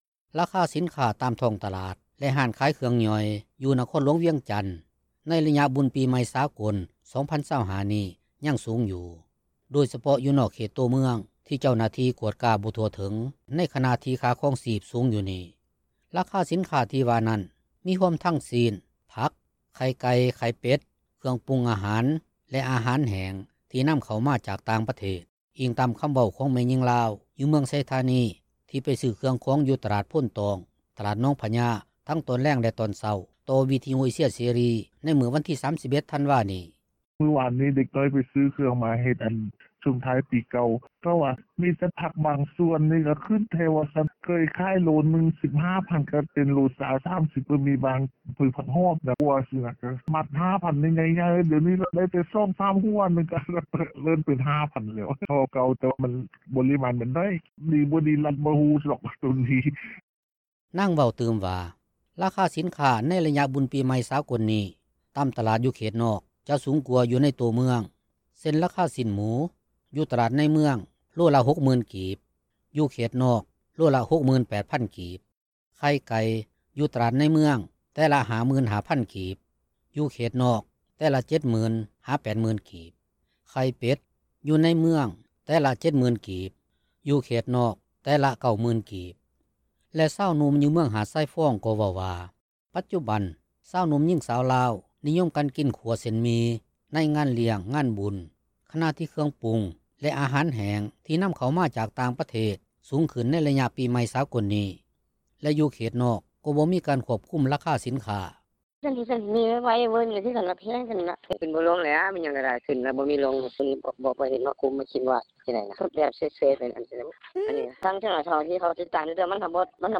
ສິນຄ້າທີ່ວ່ານັ້ນ, ຮວມມີທັງ ຊີ້ນ, ຜັກ, ໄຂ່ໄກ່, ໄຂ່ເປັດ, ເຄື່ອງປຸງອາຫານ ແລະ ອາຫານແຫ້ງ ທີ່ນໍາເຂົ້າມາ ຈາກຕ່າງປະເທດ, ອີງຕາມຄໍາເວົ້າ ຂອງແມ່ຍິງລາວ ຢູ່ເມືອງໄຊທານີ ທີ່ໄປຊື້ເຄື່ອງຂອງ ຢູ່ຕະຫຼາດໂພນຕ້ອງ, ຕະຫຼາດໜອງພະຍາ ທັງຕອນແລງ ແລະ ຕອນເຊົ້າ ຕໍ່ວິທຍຸເອເຊັຽເສຣີ ໃນມື້ວັນທີ 31 ທັນວາ ນີ້ວ່າ: